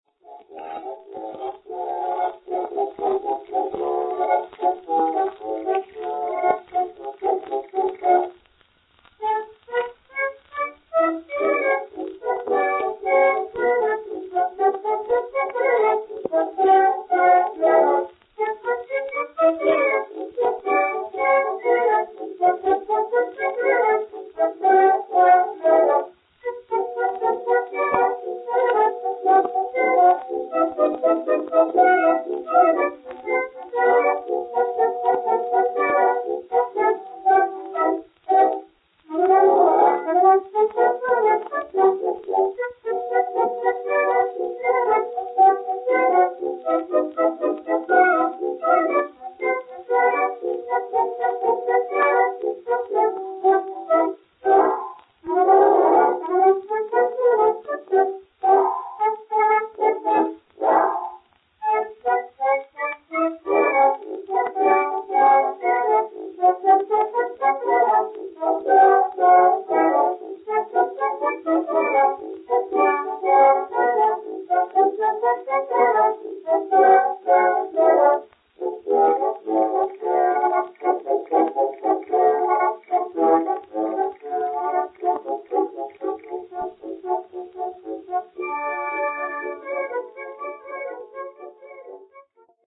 лейб-гвардии Волынского полка (скачать фрагмент)